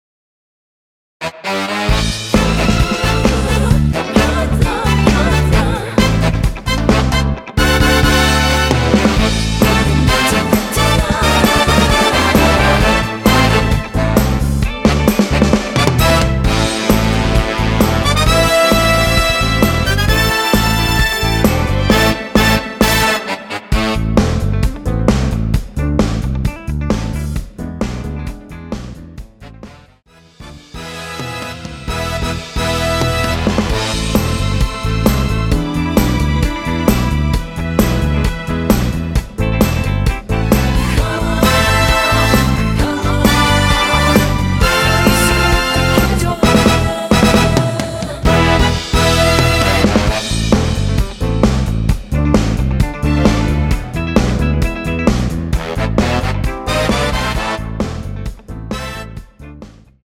원키 코러스 포함된 MR입니다.
Am
앞부분30초, 뒷부분30초씩 편집해서 올려 드리고 있습니다.
중간에 음이 끈어지고 다시 나오는 이유는